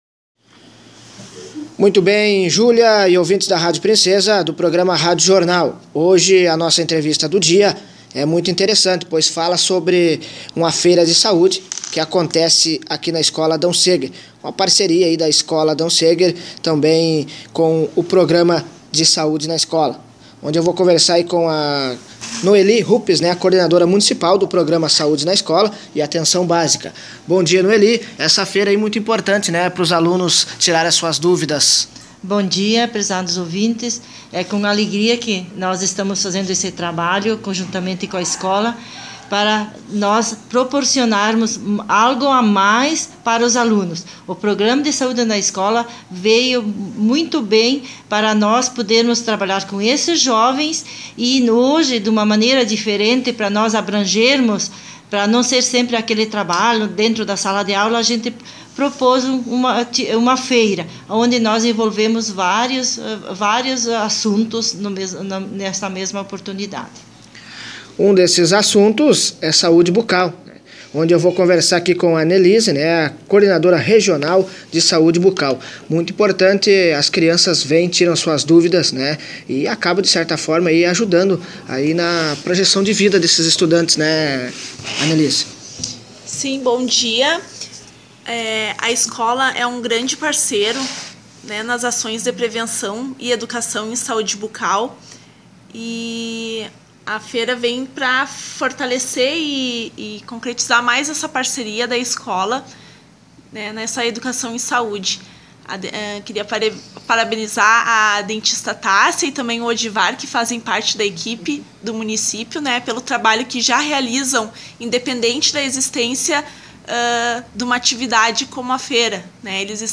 Na manhã desta sexta-feira (15), a Rádio Princesa esteve presente na Escola Adão Seger para acompanhar a Feira de Saúde do Programa de Saúde na Escola, uma parceria entre a Escola Adão Seger e a Secretária Municipal de Saúde de Selbach. Abaixo você confere a entrevista completa, trazendo informações sobre esse projeto que é realizado desde 2017.
Entrevista-Feira-da-Saúde.mp3